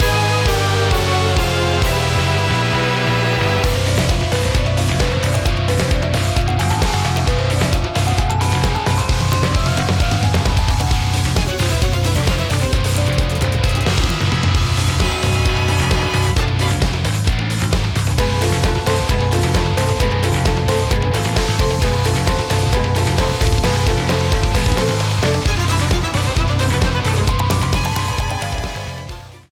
Ripped from the game
faded out the last two seconds